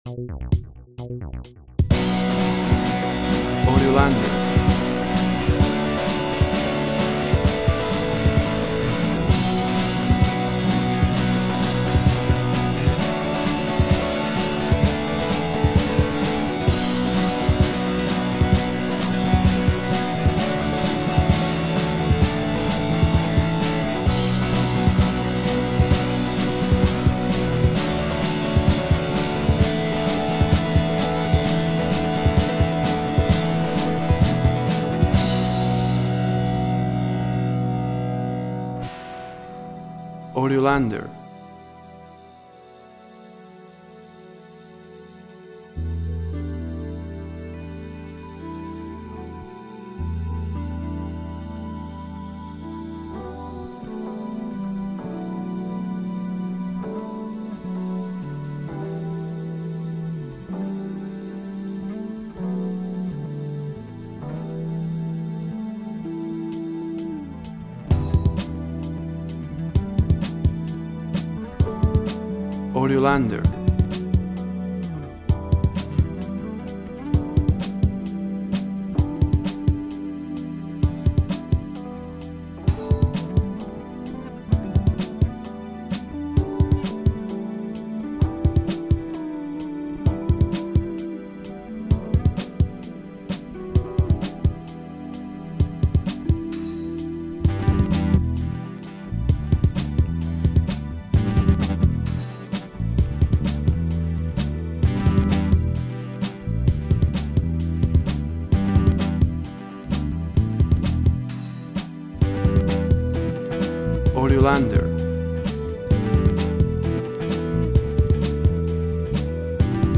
Instrumental Bass minus vocal.
Tempo (BPM) 120